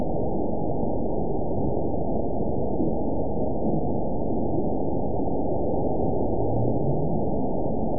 event 918001 date 04/26/23 time 15:04:16 GMT (2 years ago) score 9.32 location TSS-AB04 detected by nrw target species NRW annotations +NRW Spectrogram: Frequency (kHz) vs. Time (s) audio not available .wav